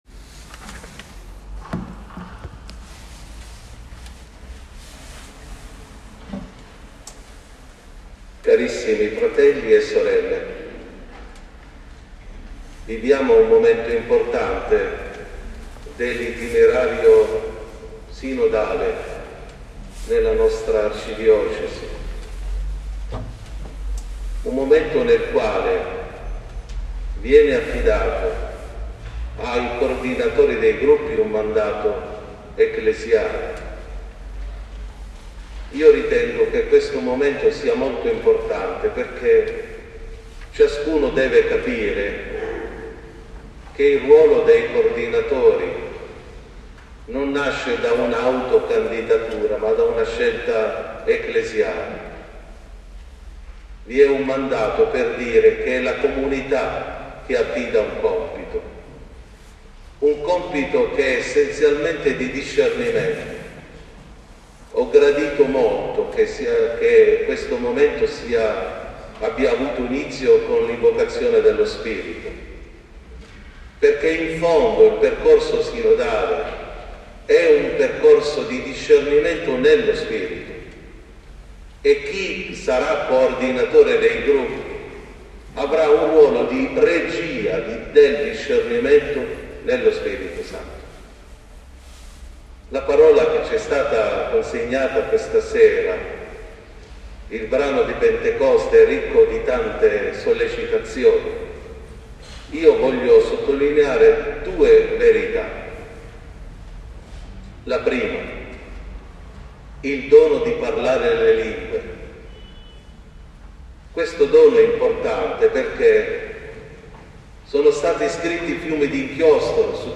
Ascolta la riflessione di Mons. Angelo Raffaele Panzetta
Mandato ai Coordinatori dei Gruppi Sinodali della Diocesi (Parrocchia San Giovanni Battista (Montepaone Lido) – 15 Dicembre 2021 ) Photogallery Ascolta la riflessione di Mons.